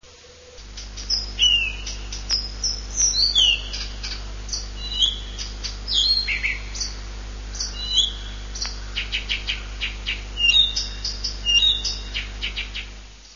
А сейчас запоет камышевка
kamyshevka.mp3